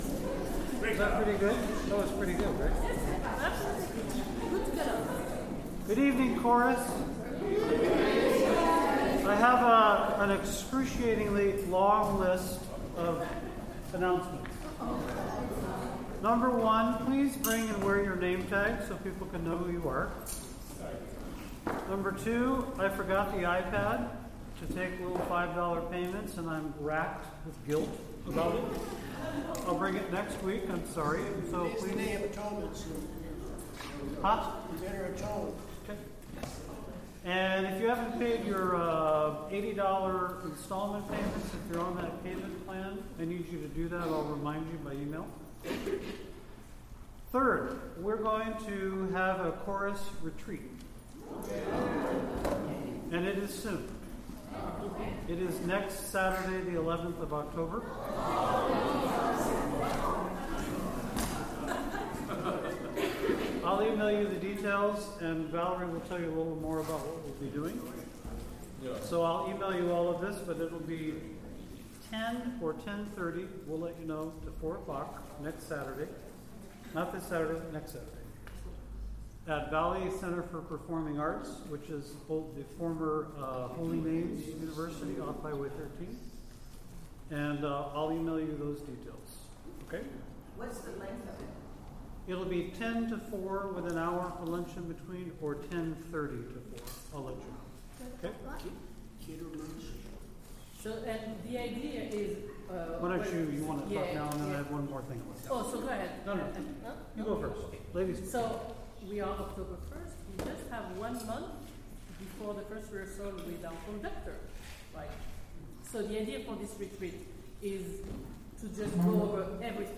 OSC Rehearsal, Wednesday, October 1, 2025